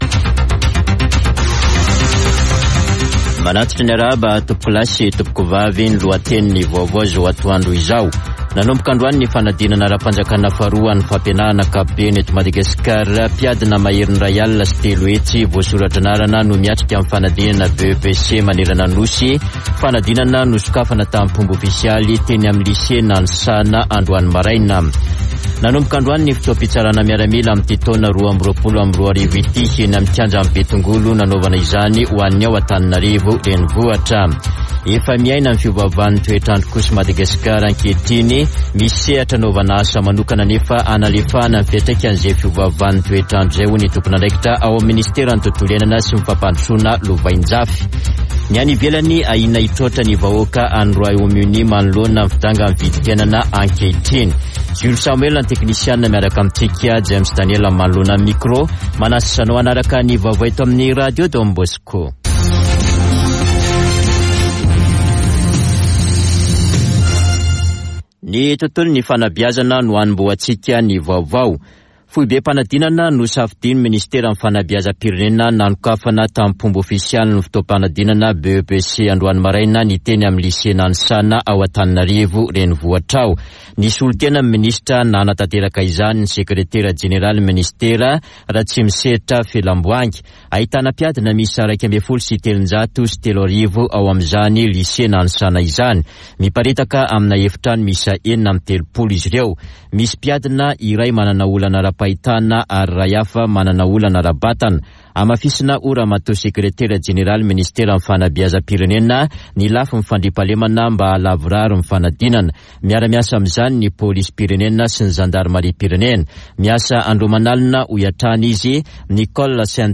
[Vaovao antoandro] Alatsinainy 04 jolay 2022